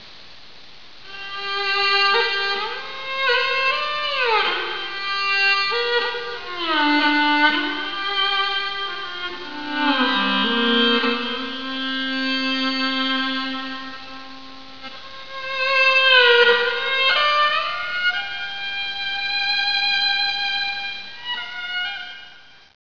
内モンゴルの著名な曲を中心にレコーディングしました。
馬頭琴の魅力たっぷりのCDです。